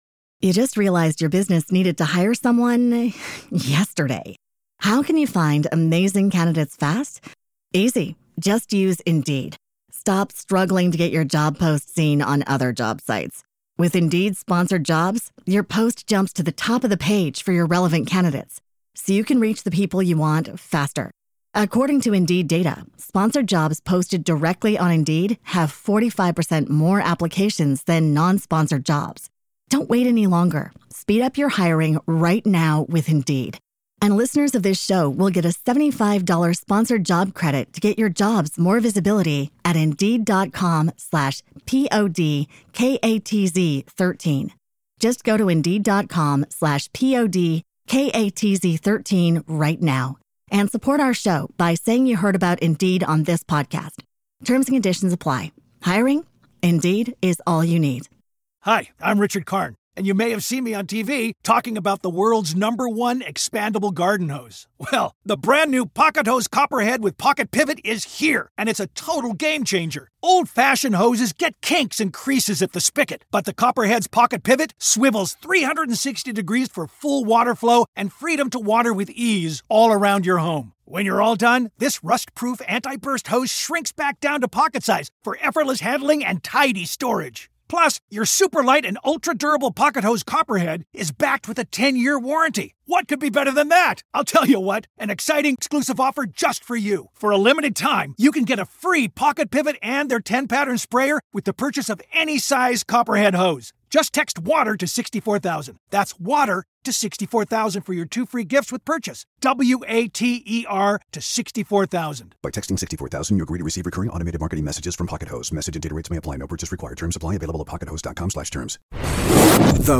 (Interview starts at 16 minutes 3 seconds) Plus the Top 5 Most Followed Companies & the Top 5 Most Liked RNS’s on Vox Markets in the last 24 hours.